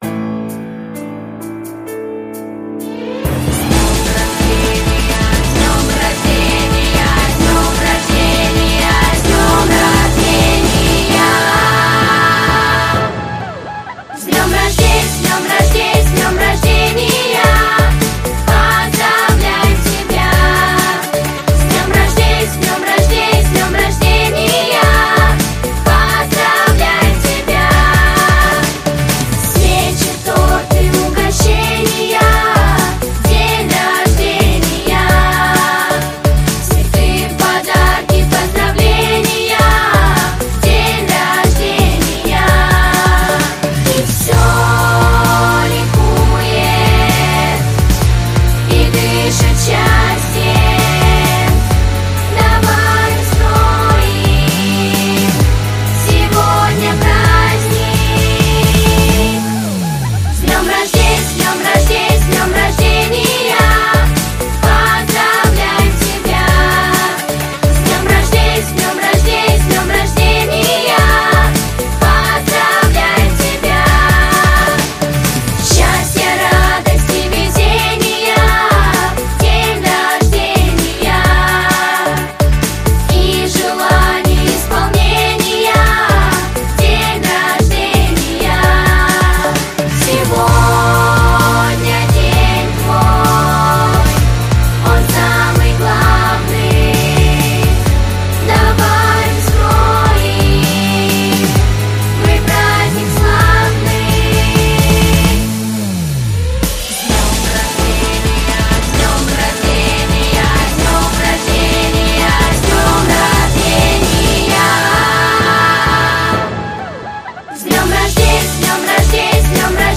песня.